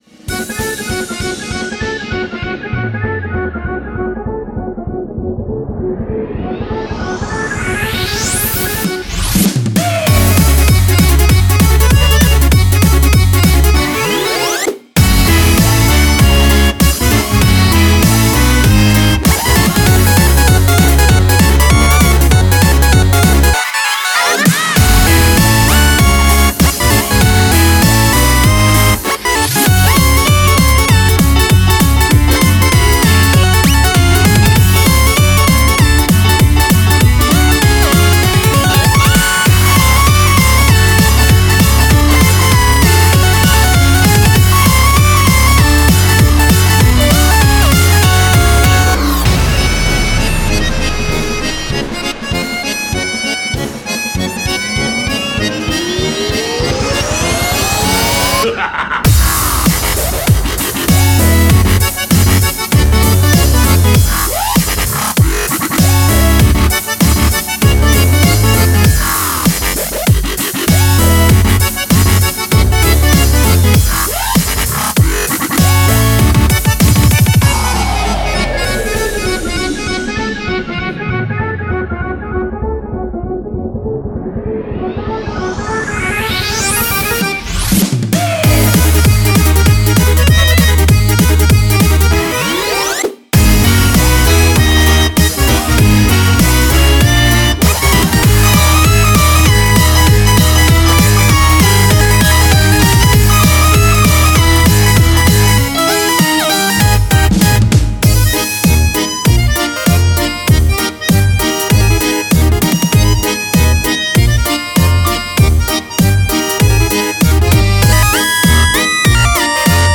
BPM98-196
Audio QualityMusic Cut